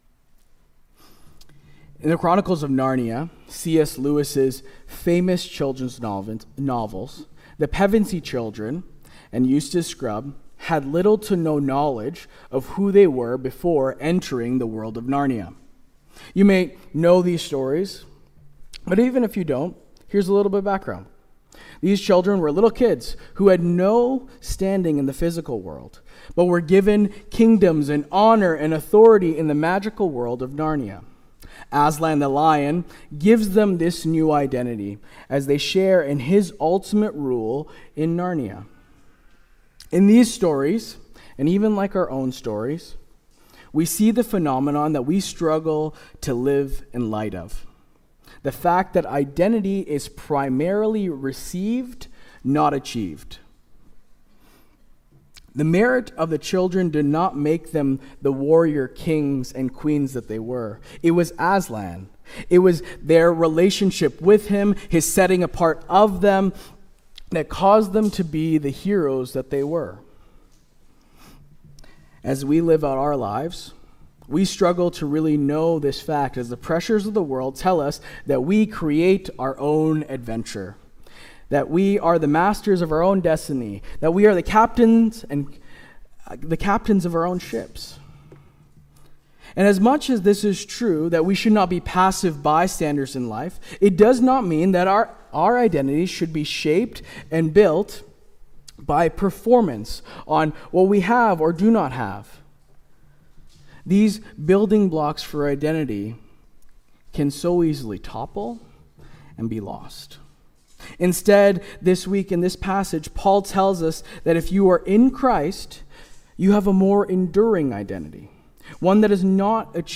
Sermon “The Identity Project”